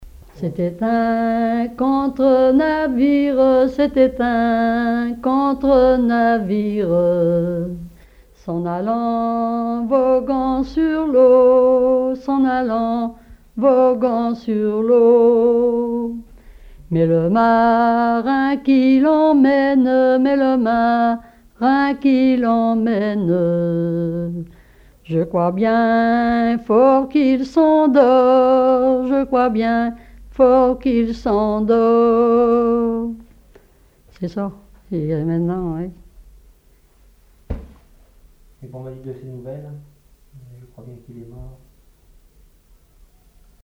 Genre strophique
collecte en Vendée
Témoignages et chansons traditionnelles
Pièce musicale inédite